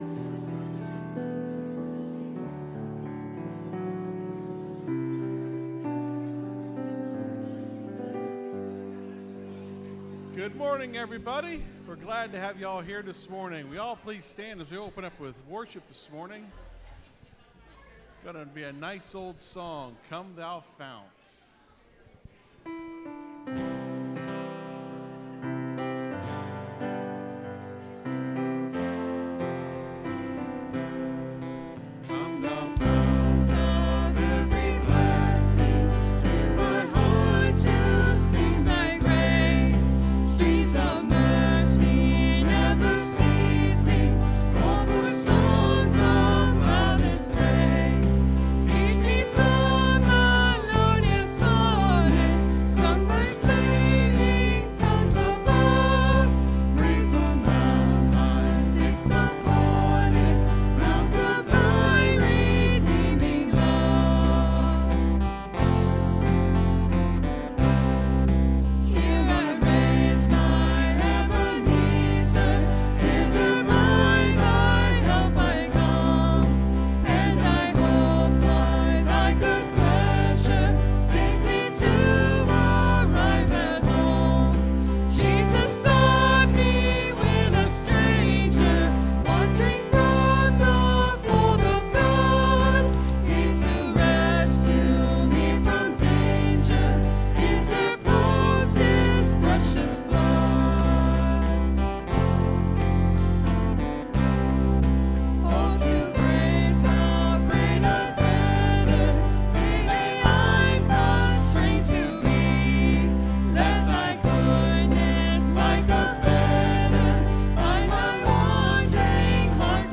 (Sermon starts at 24:00 in the recording).